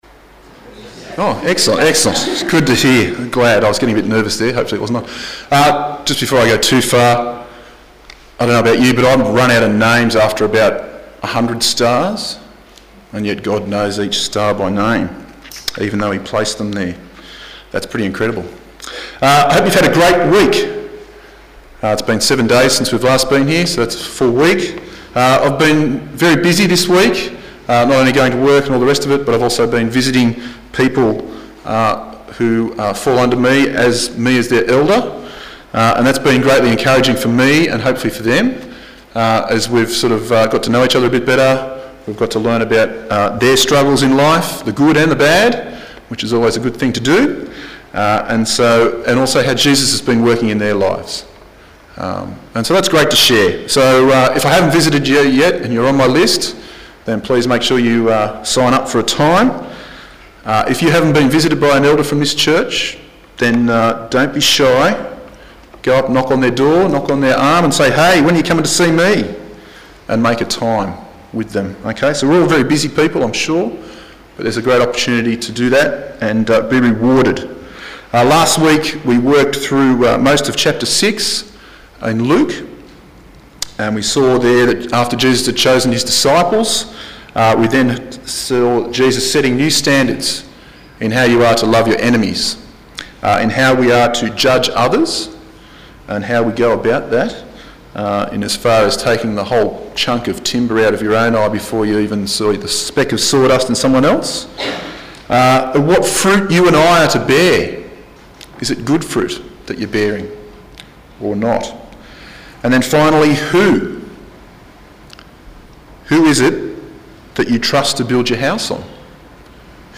Hebrews 11:1-31 Service Type: Sunday Morning « What do Jesus’ disciples look like?